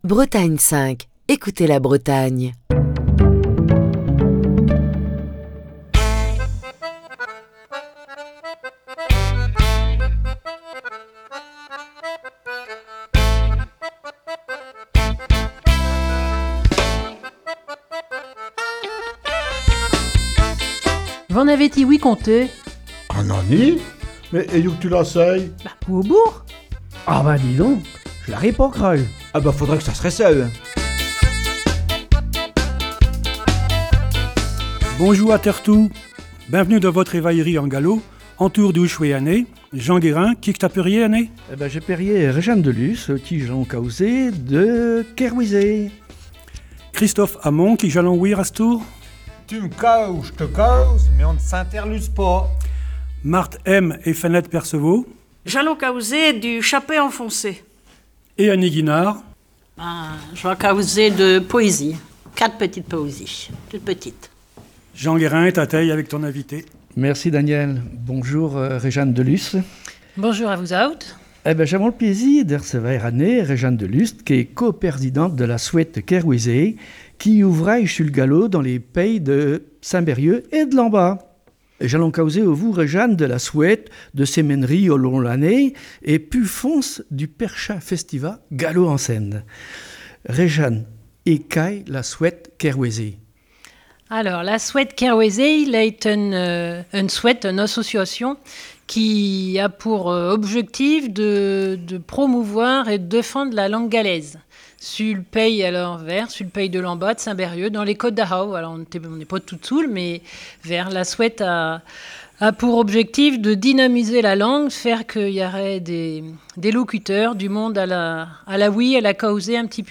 V'en avez ti-ouï conté, en direct depuis Ti Ar Vro Saint-Brieuc. Aujourd'hui, l'association Qerouezée | Bretagne5